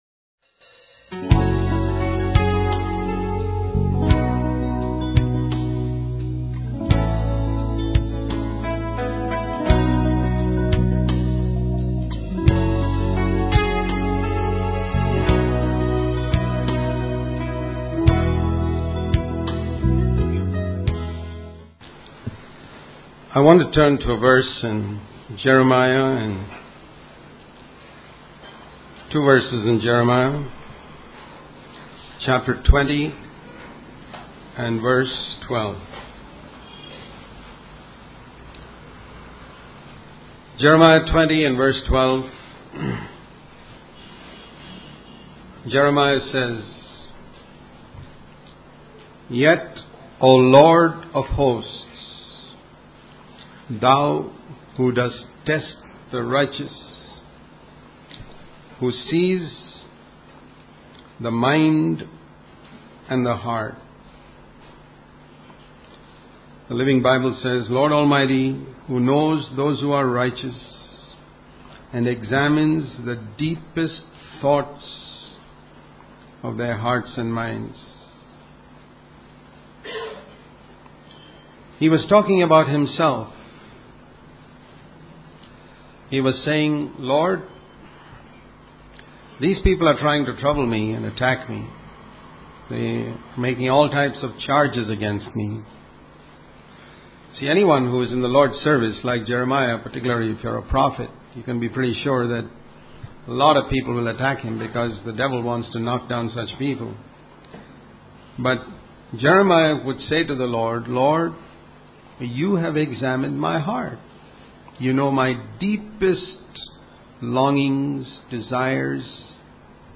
In this sermon, the speaker shares a personal experience of losing his sermon transcript and how he believes God allowed it to happen because the words were not strong enough. He then references the story of Jeremiah and how God instructed him to rewrite the scroll with even stronger words of judgment.